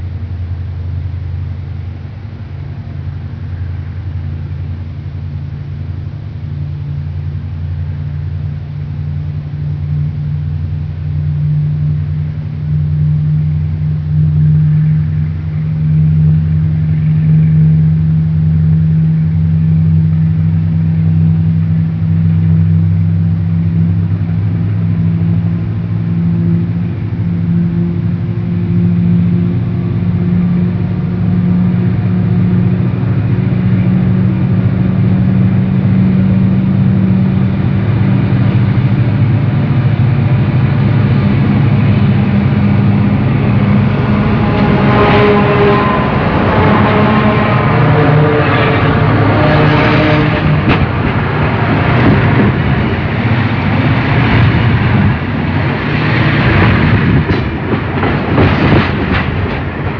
C30-7 and SD40-2 roar above the microphone.
Features great traction motor sounds!